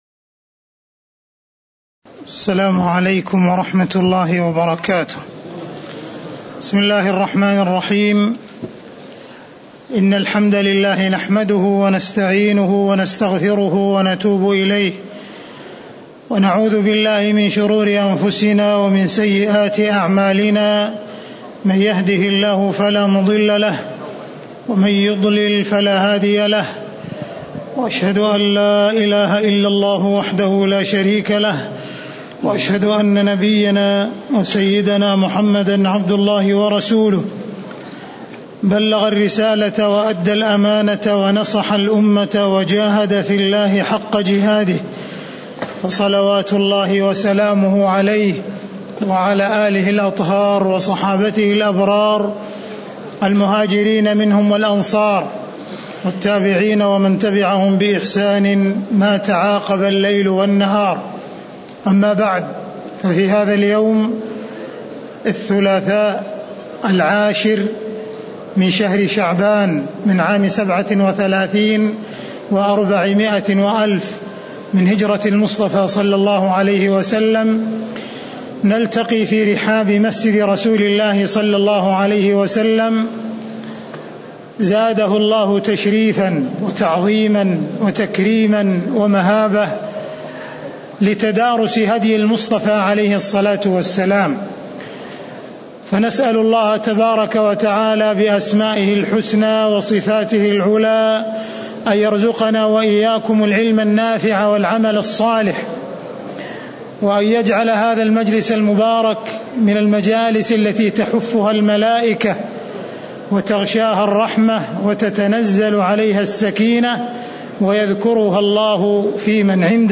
تاريخ النشر ١٠ شعبان ١٤٣٧ المكان: المسجد النبوي الشيخ: معالي الشيخ أ.د. عبدالرحمن بن عبدالعزيز السديس معالي الشيخ أ.د. عبدالرحمن بن عبدالعزيز السديس فضائل المدينة (13)